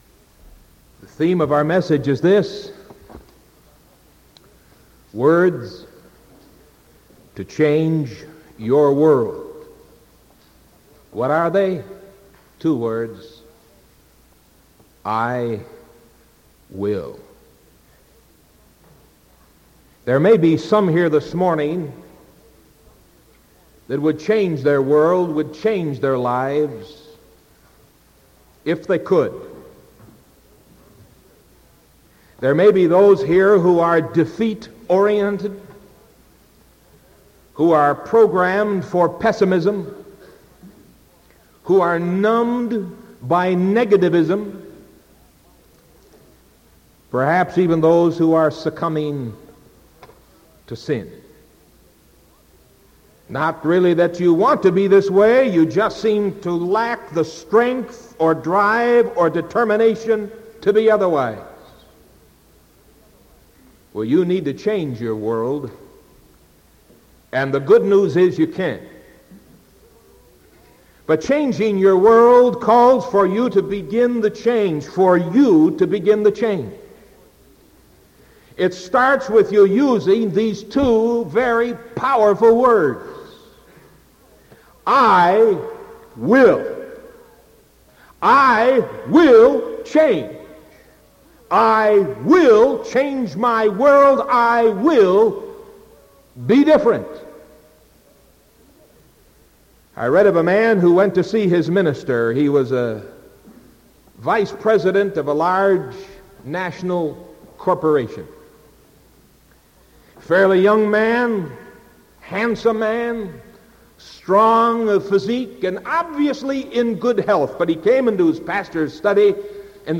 Sermon June 8th 1975 AM